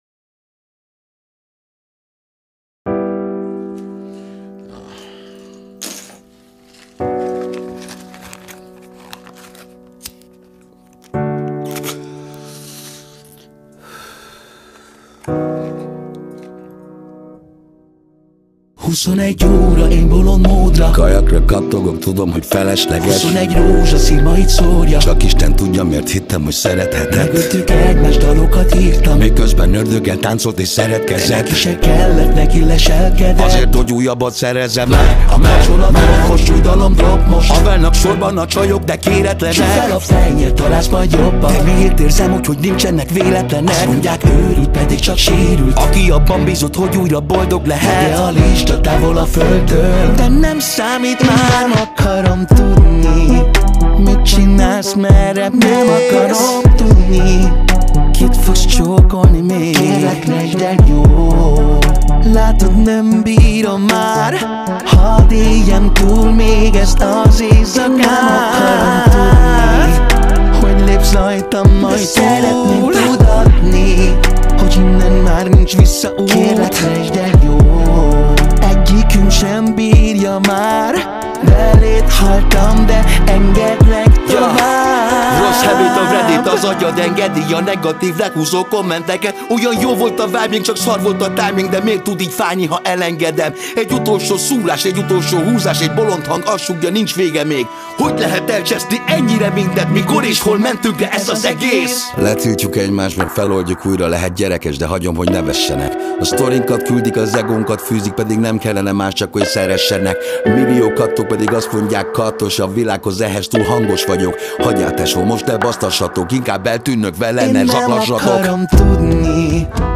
Zongora
Gitár
Trombita